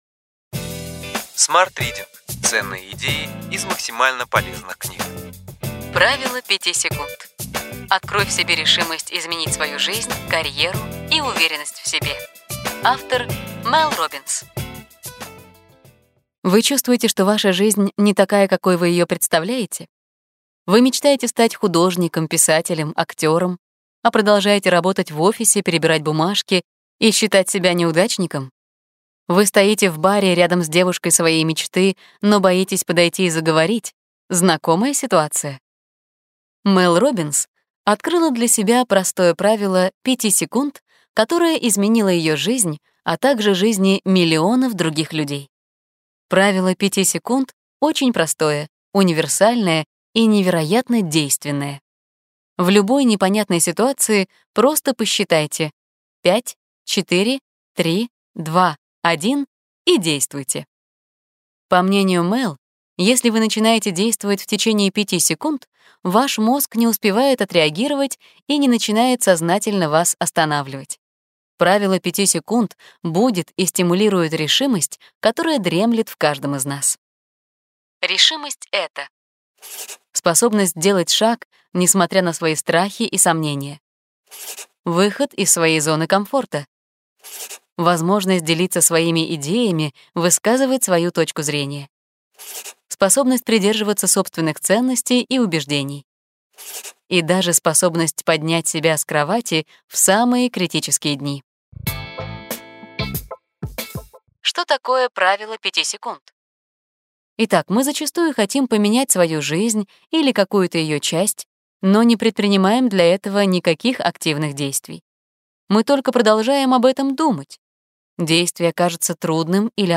Аудиокнига Ключевые идеи книги: Правило пяти секунд. Открой в себе решимость изменить свою жизнь, карьеру и уверенность в себе. Мел Роббинс | Библиотека аудиокниг